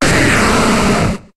Cri de Tyranocif dans Pokémon HOME.